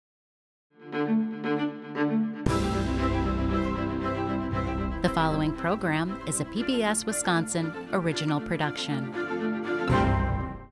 PBS WI- ORIGINAL PROD HEADER 10_Female_REV 21.wav